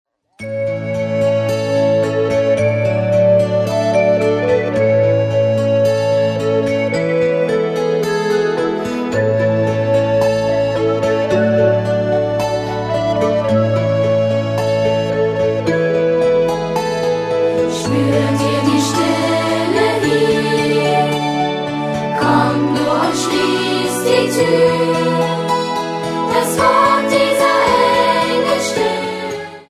Ein Weihnachts-Minimusical